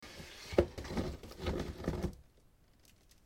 Download Drag Race sound effect for free.